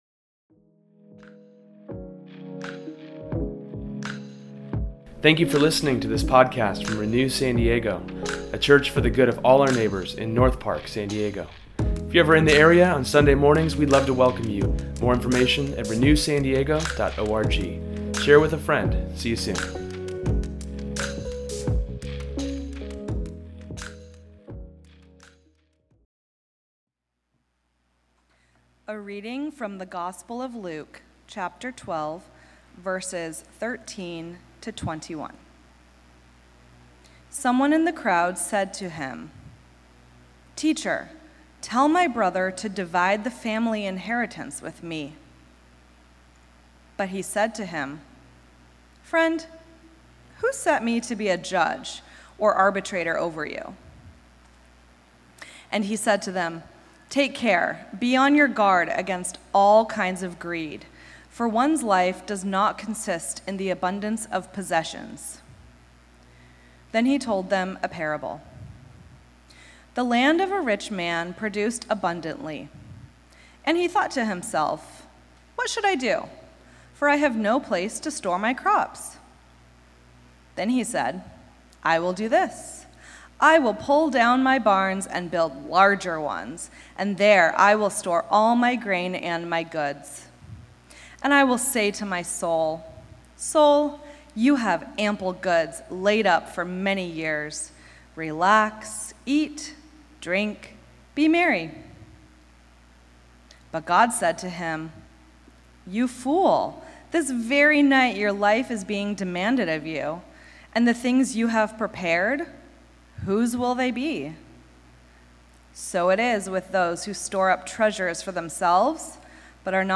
In today’s sermon, we explore the danger of greed, and how to beware, recognize signs, and move towards being healed.